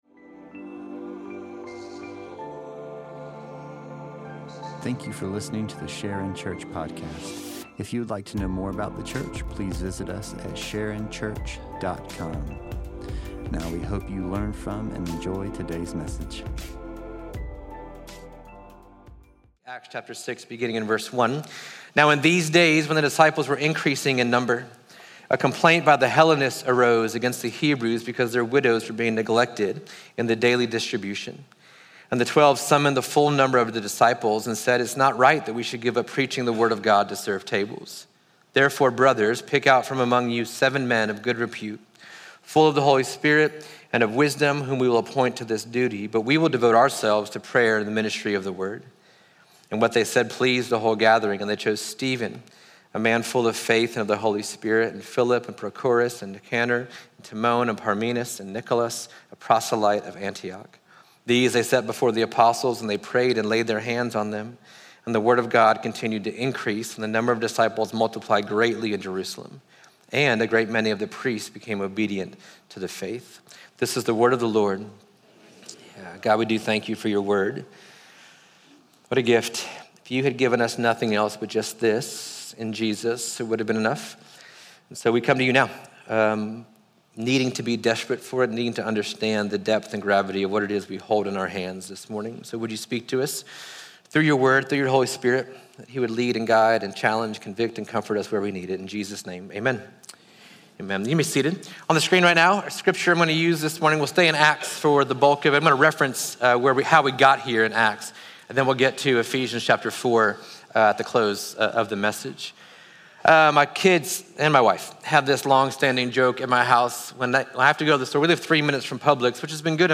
Sharon Church | Sermons